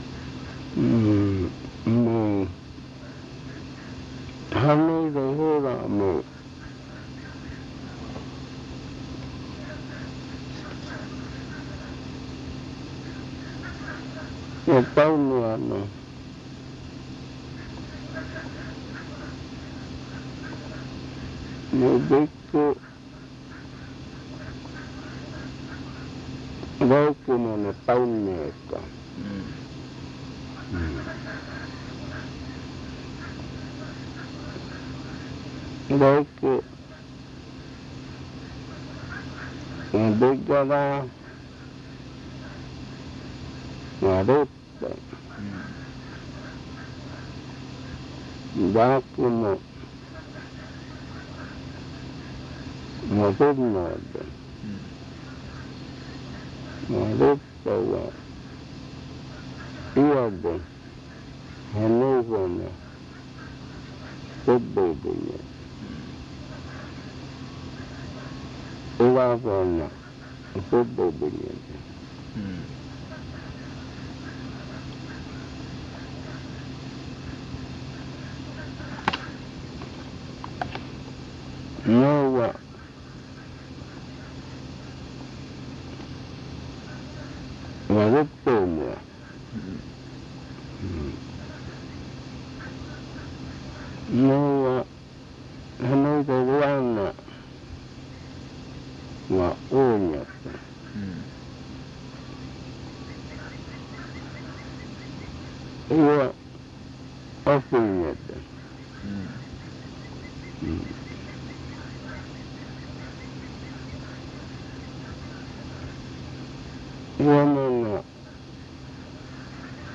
Explicación y canto de la conjuración para curar la contaminación (jenuizaɨ) de un niño pequeño causada por la acción de la naturaleza. Aquí se nombra la candela, y una serie de aves y animales que consumen comida cruda y nos les pasa nada.